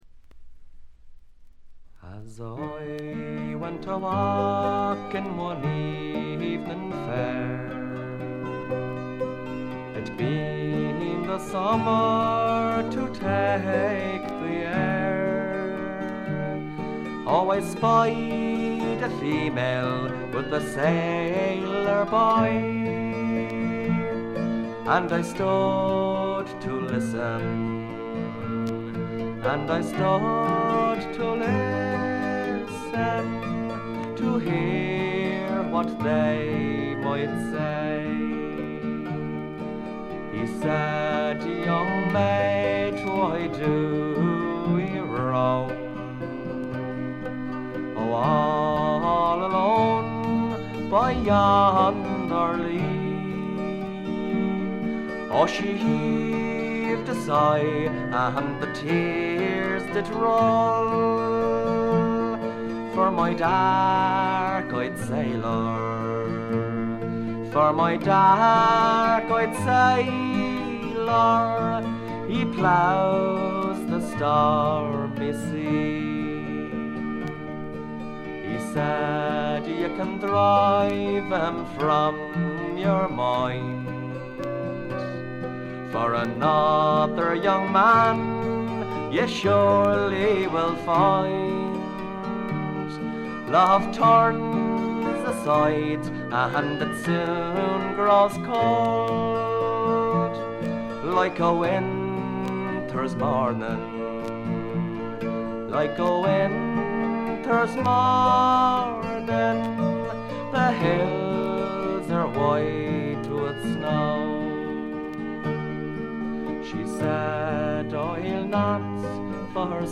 ところどころでチリプチ。散発的なプツ音が2回ほど。
アイリッシュ・フォーク基本中の基本です。
中身は哀切なヴォイスが切々と迫る名盤。
試聴曲は現品からの取り込み音源です。